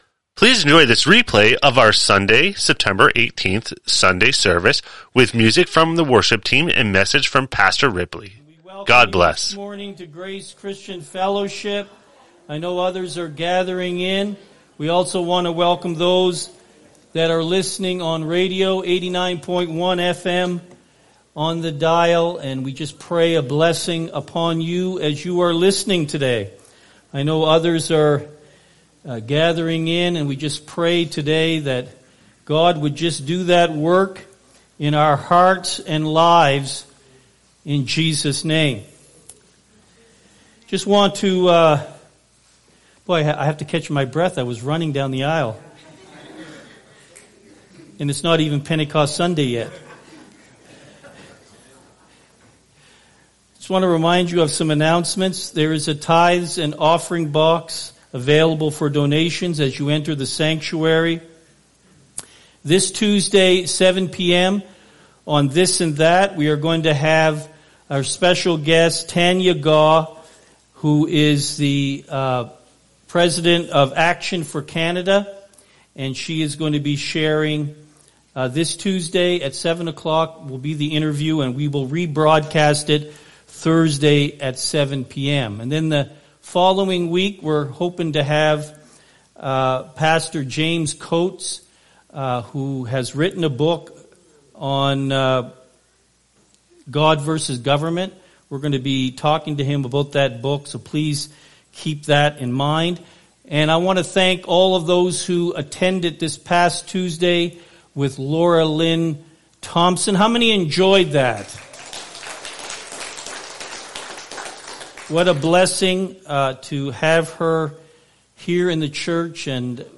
Sermons | Grace Christian Fellowship